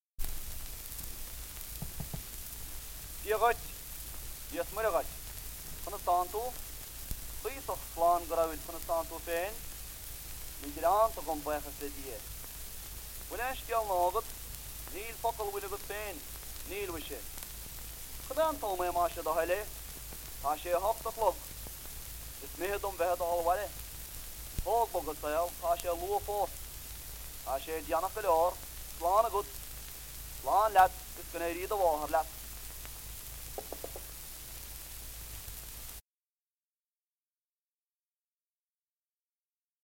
Sample dialogue